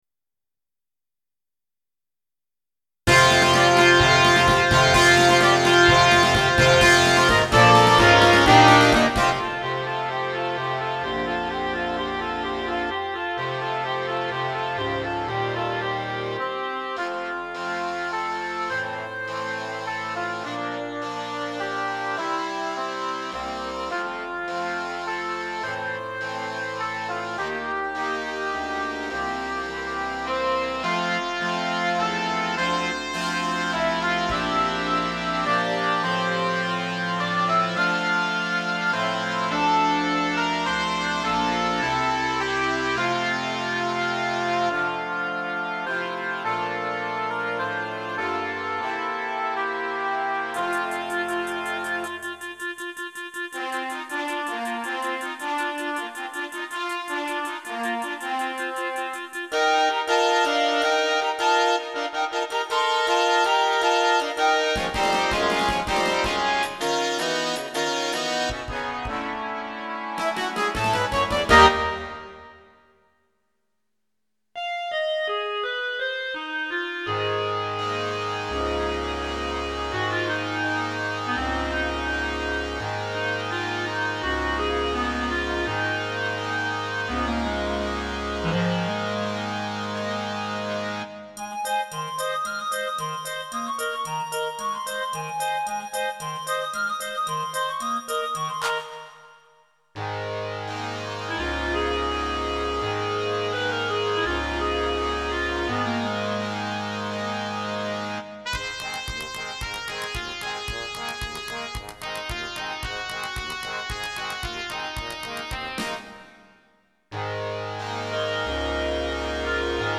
Instrumentation: Standard Concert Band